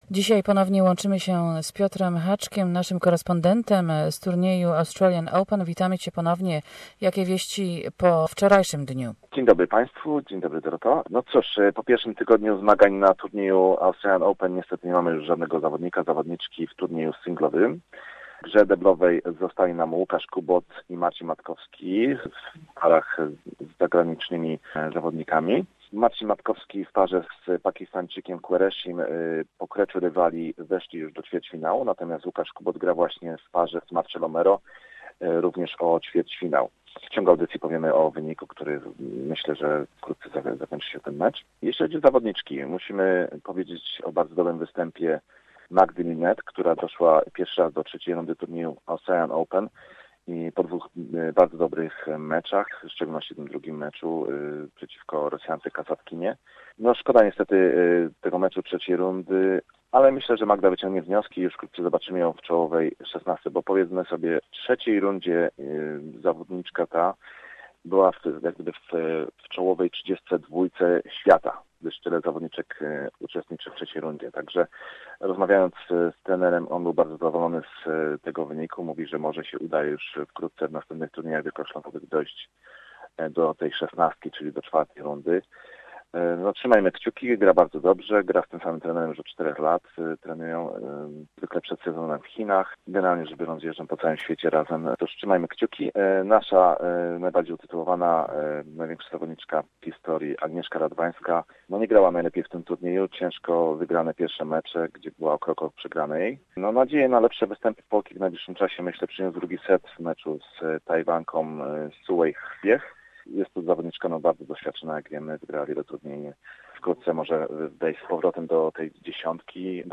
reporting from MELBOURNE. Among the latest: Bulgarian Grigor Dimitrov held his nerve to knock home favourite Nick Kyrgios out of the Australian Open in a fourth-round thriller at the Rod Laver Arena on Sunday.